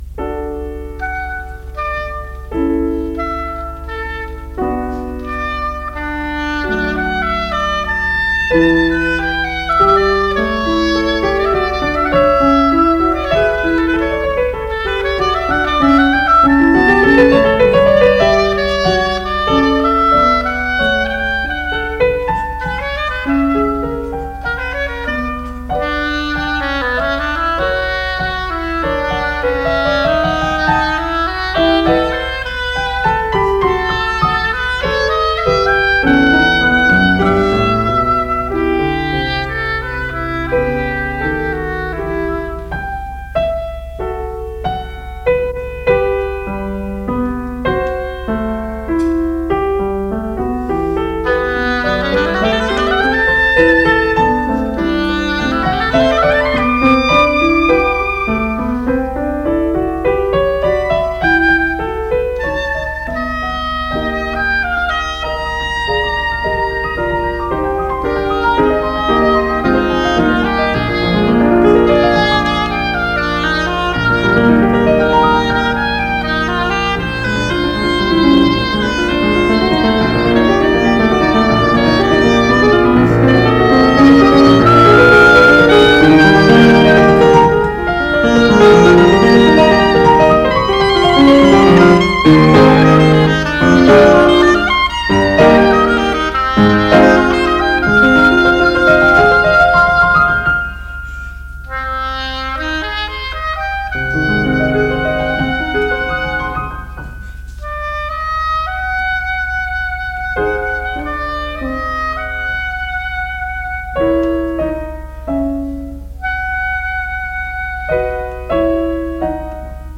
piano
Style: Classical
oboe
sonata-for-oboe-and-piano-op-166.mp3